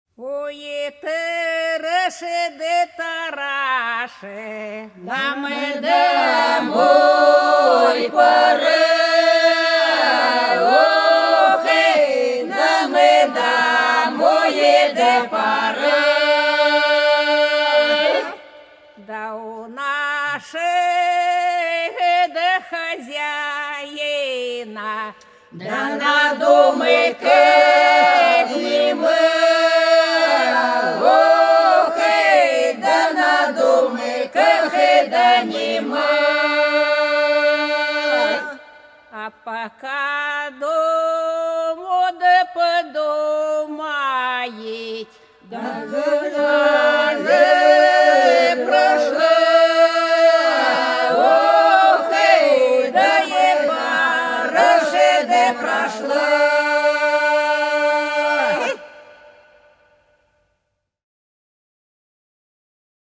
По-над садом, садом дорожка лежала Тара, тара, нам домой пора - свадебная (с.Фощеватово, Белгородская область)
30_Тара,_тара,_нам_домой_пора_(свадебная).mp3